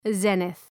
Προφορά
{‘zi:nıɵ}
zenith.mp3